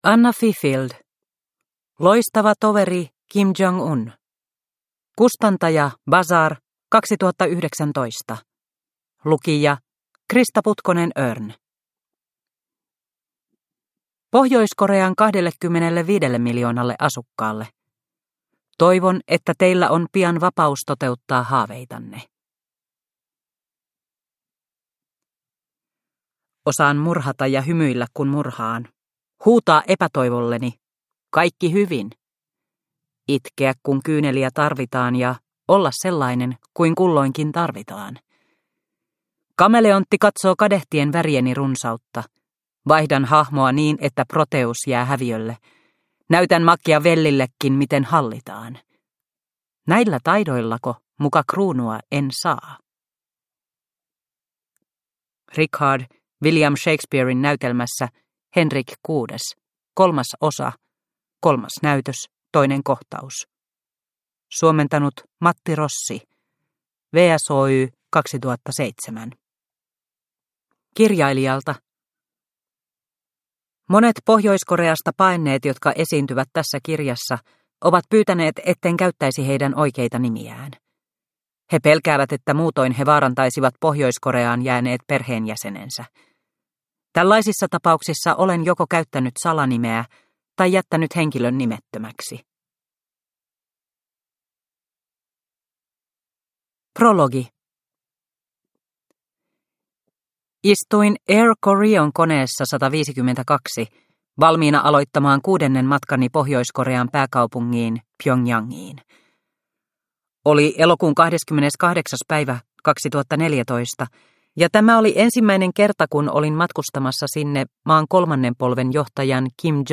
Loistava toveri Kim Jong Un – Ljudbok – Laddas ner